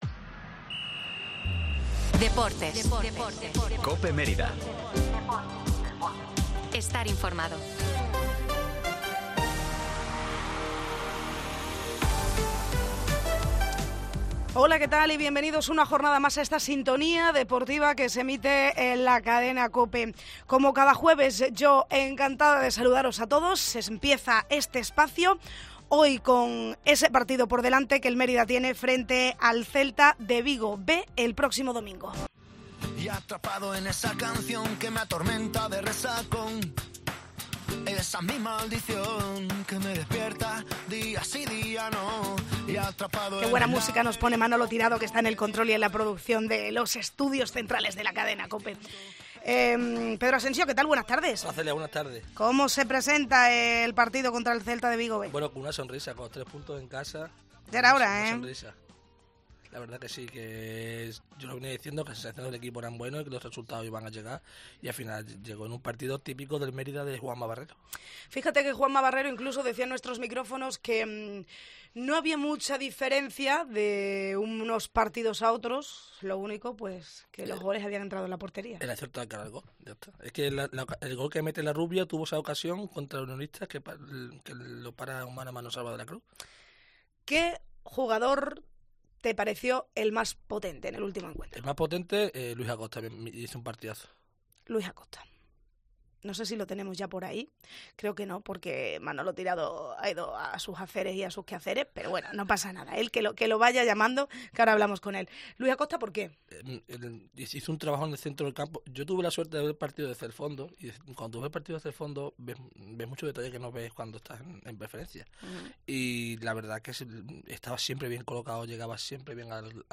Tertulia del Mérida en COPE